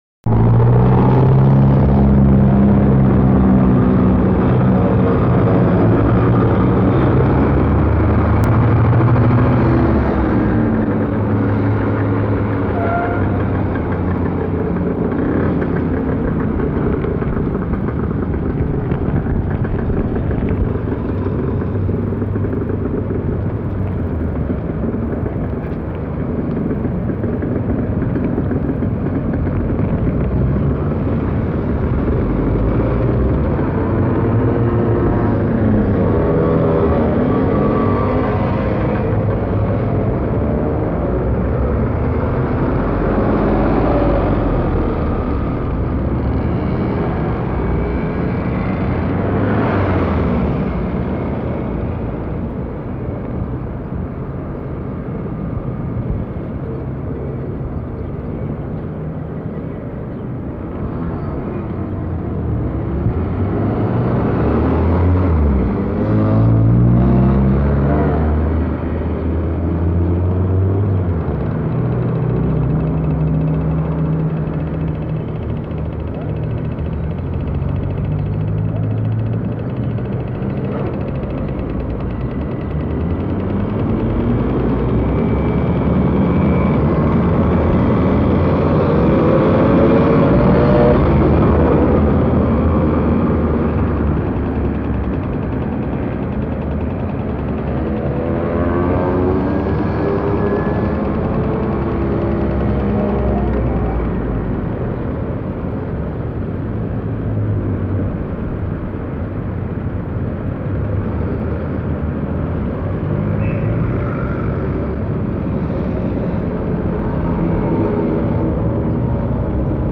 audio recording (12.35 min.) at traffic lights crossing Boulevard Heuvelink and Johan de Wittlaan, Arnhem at 7.30 am Listen to 'humanae vitae'